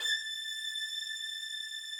strings_081.wav